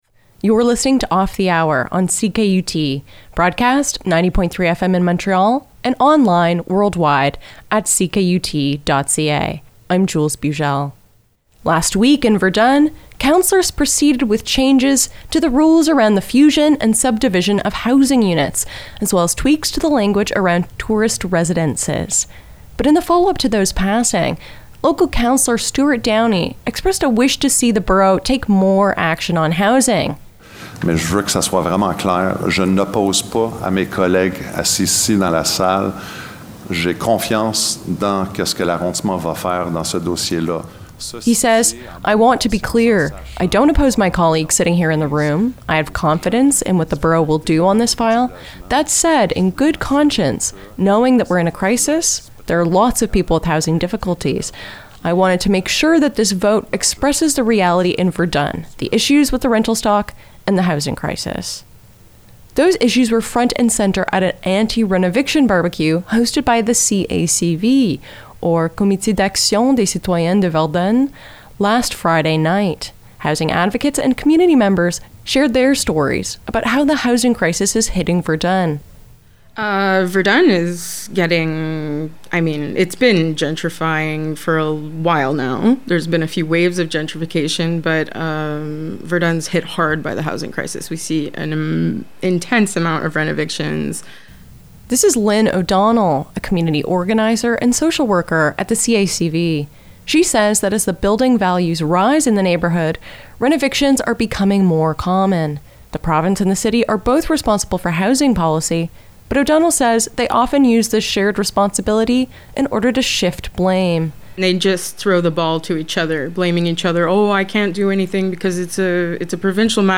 The housing crisis was top of mind at an anti-renoviction barbecue in Verdun last Friday (Sept 8).
Organizers and community members took the mic to share their experiences of rising rents, political inaction, and hostile landlords.